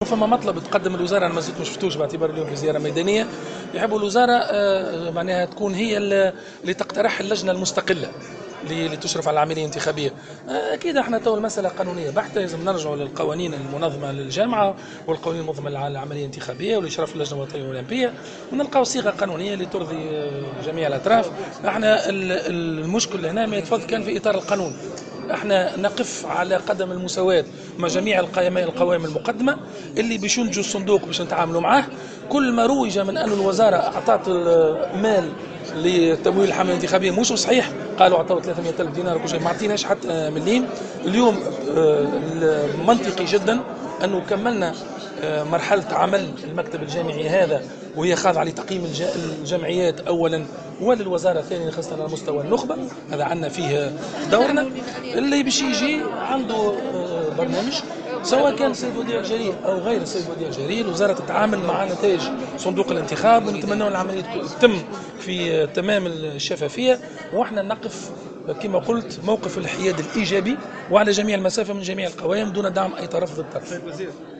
أكد وزير الشباب و الرياضة السيد ماهر بن ضياء في تصريح لمراسل جوهرة أف أم على هامش زيارة أداها إلى ولاية القيروان أن الوزارة ستنظر في مطلب تعيين هيئة مستقلة تشرف على إنتخابات الجامعة التونسية لكرة القدم في حدود ما تسمح به القوانين مشيرا أن الوزارة تقف على قدم المساواة مع جميع القوائم المقدمة.